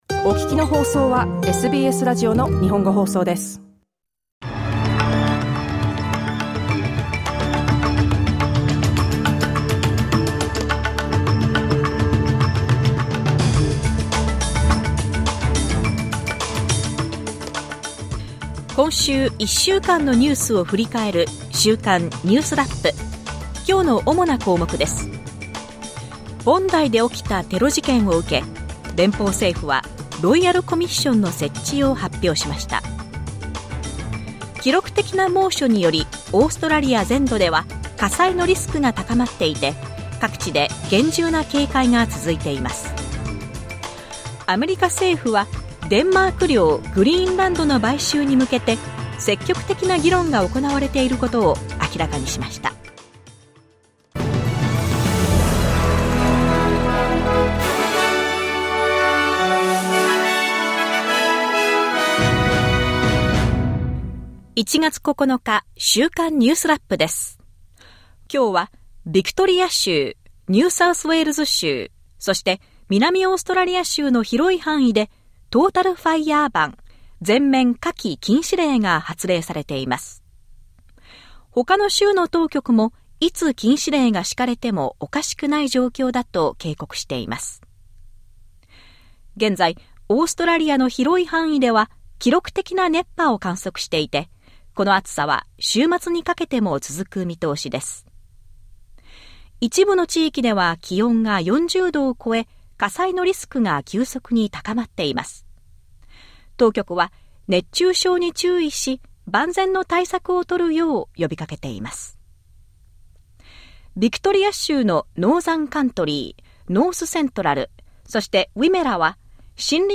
SBS Japanese Weekly News Wrap Saturday 10 January